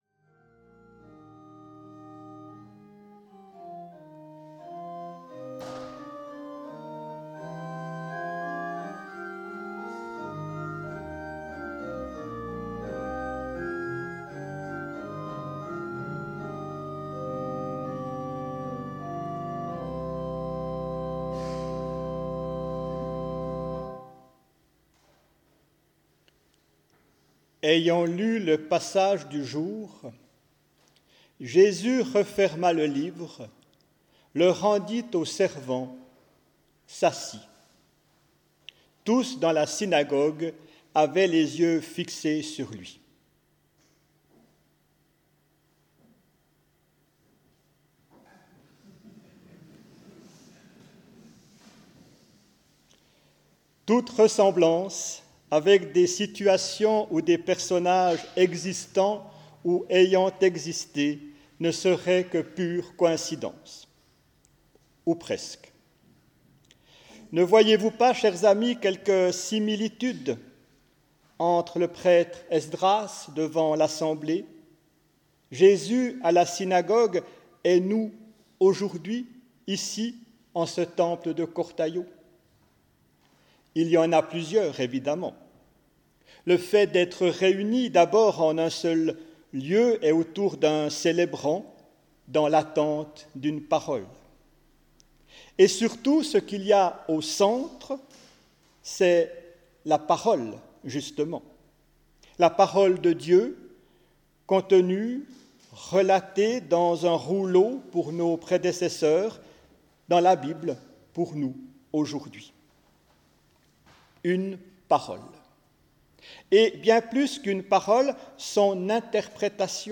Quelques prédications que j'ai prononcées en divers endroits, principalement lors de cultes dominicaux.
Prédication du dimanche 26 janvier 2025 au temple de Cortaillod (NE)
Une-parole-inspiree_predicatation.mp3